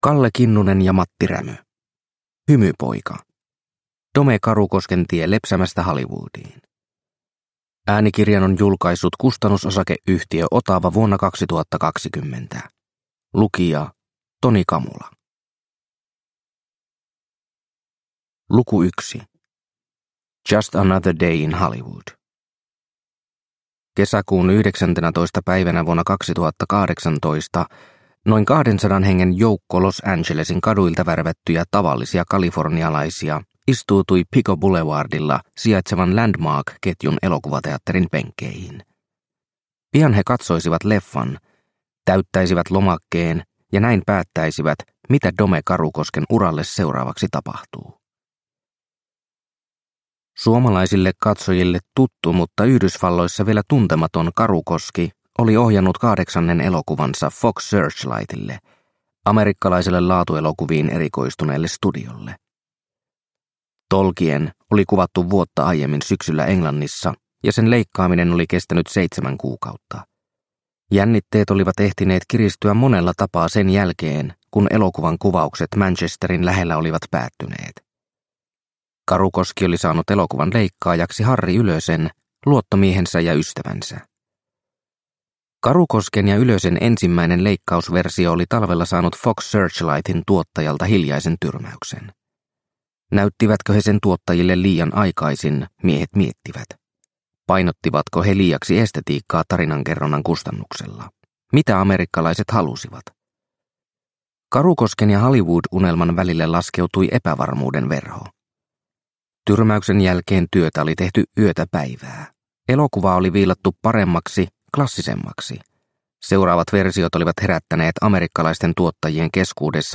Hymypoika – Ljudbok – Laddas ner